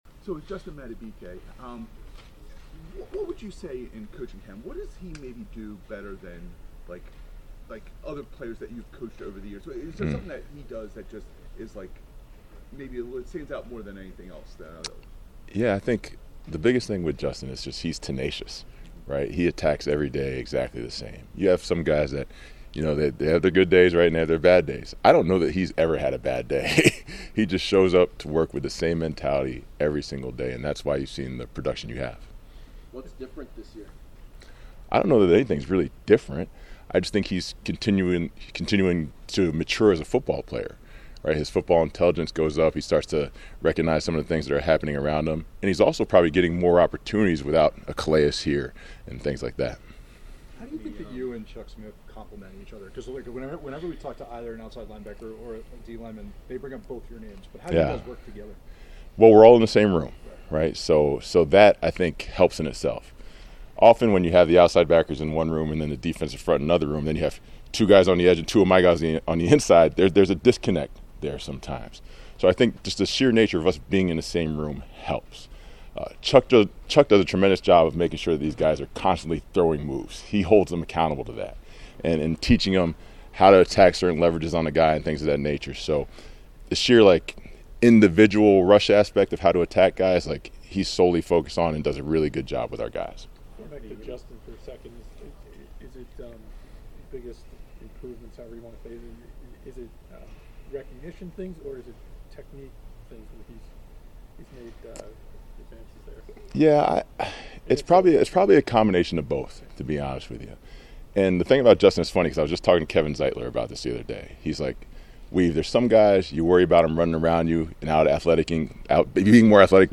Locker Room Sound